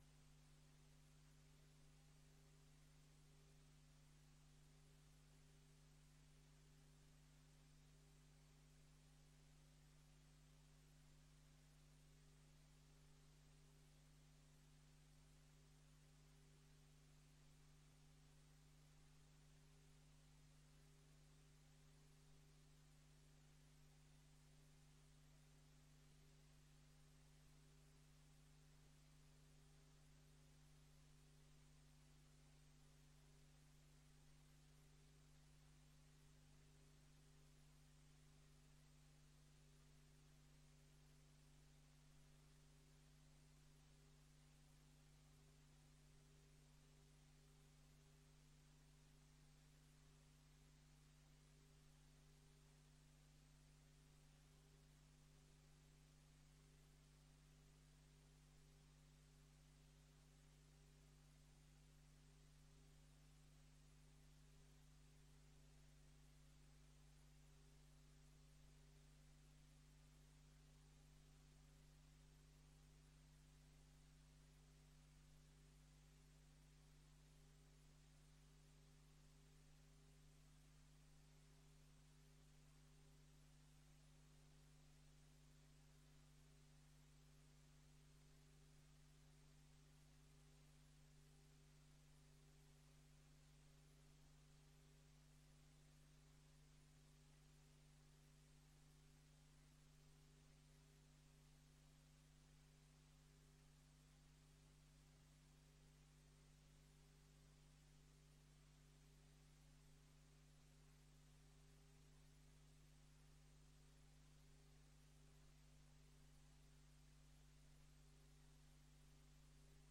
Gemeenteraad 28 mei 2024 19:30:00, Gemeente Dinkelland
Download de volledige audio van deze vergadering